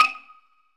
ding.ogg